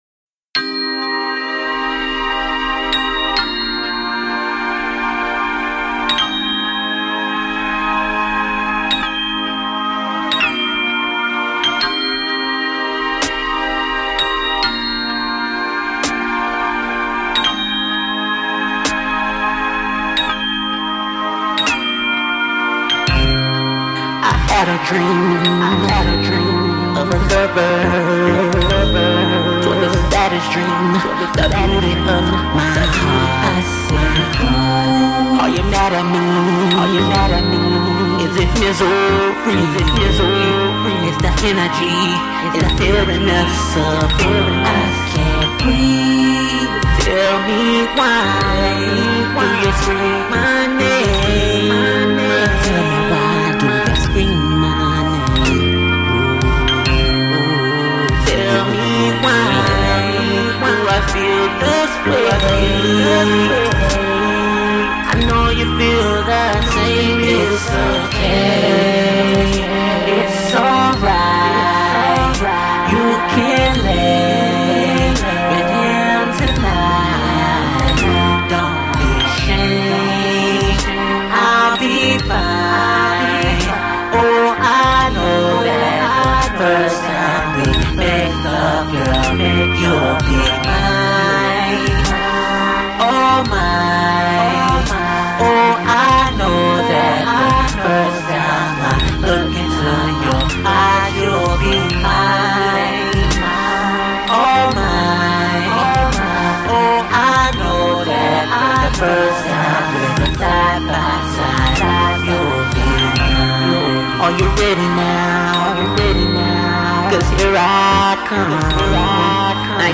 R&B/ Hip-Hop